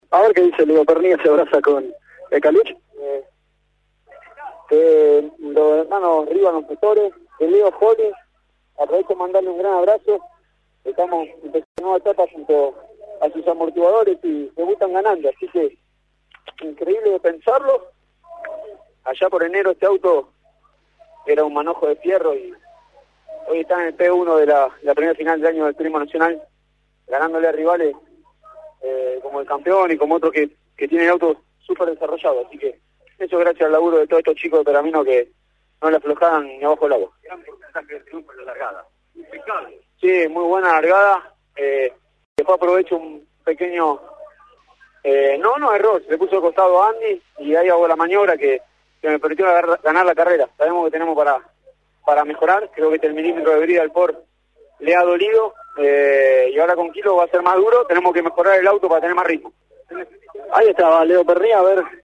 El «Tanito» pasó por los micrófonos de Pole Position y manifestó su alegría luego del triunfo obtenido en la primera fecha del Turismo Nacional en Alta Gracia. Felicitó al equipo MG-C Pergamino por el esfuerzo hecho para contar con la unidad nueva en pista para este fin de semana.